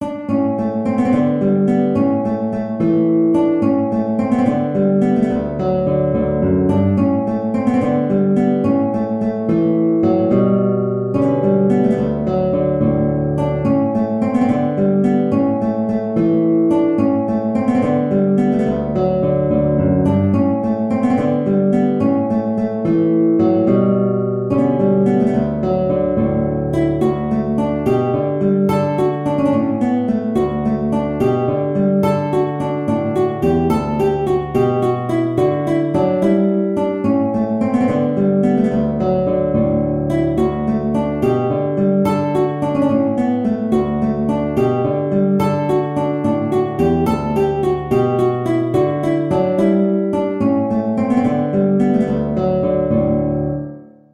6/8 (View more 6/8 Music)
Guitar  (View more Intermediate Guitar Music)